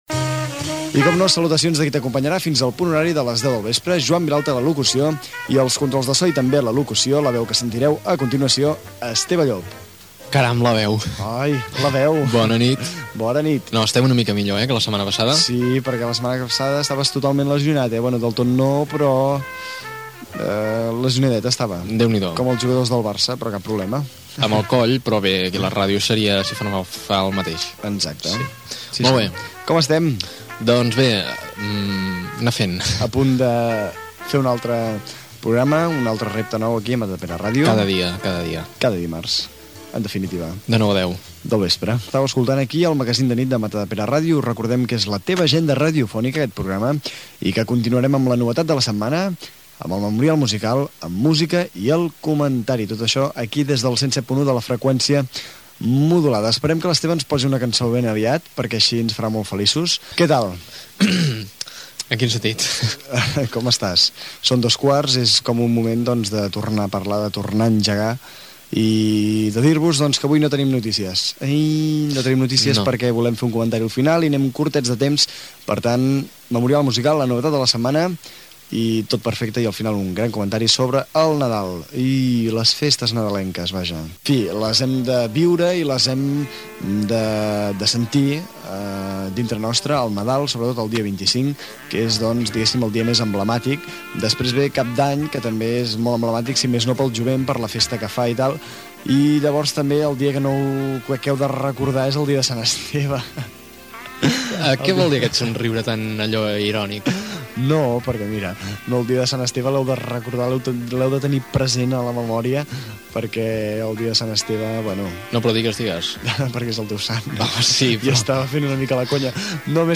Magazine de nit
Equip del programa, diàleg inicial, sumari de continguts
Entreteniment
FM